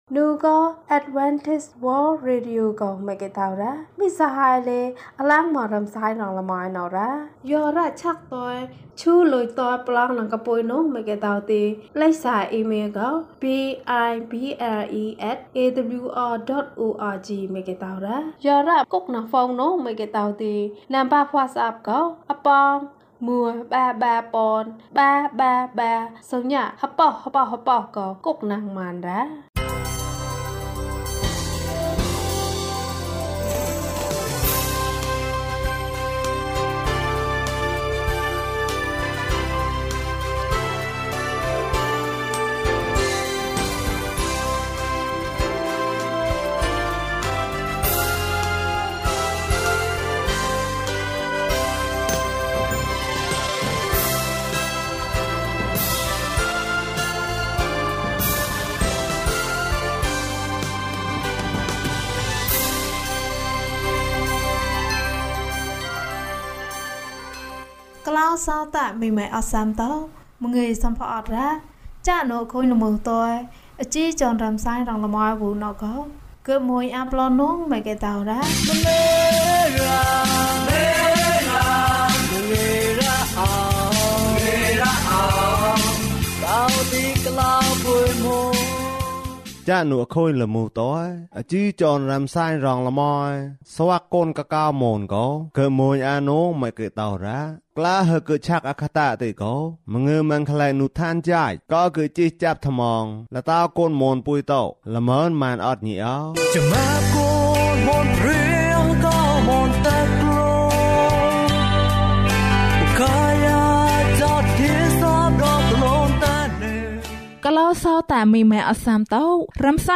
ငါနှင့်အတူအရာအားလုံး။ ကျန်းမာခြင်းအကြောင်းအရာ။ ဓမ္မသီချင်း။ တရားဒေသနာ။